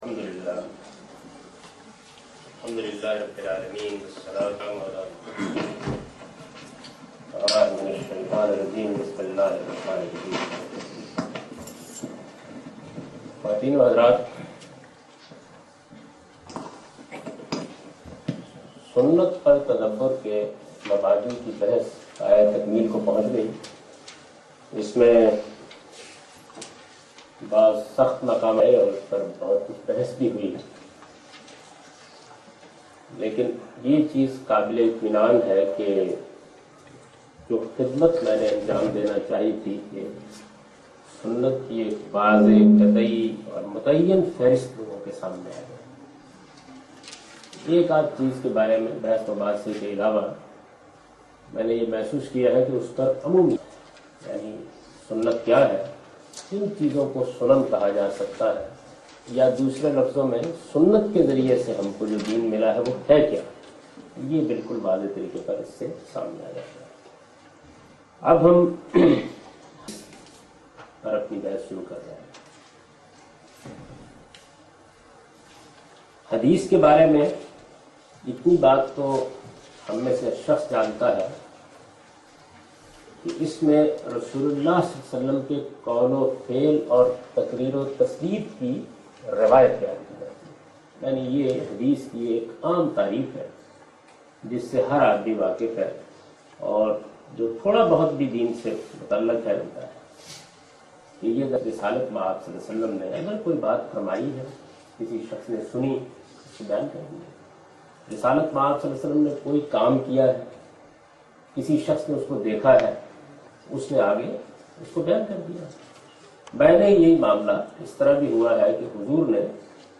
A comprehensive course on Islam, wherein Javed Ahmad Ghamidi teaches his book ‘Meezan’.
In this lecture series, he not only presents his interpretation of these sources, but compares and contrasts his opinions with other major schools developed over the past 1400 years. In this lecture he introduces fundamental principles to understand Hadith.